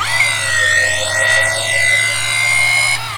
01673-robotics-move.wav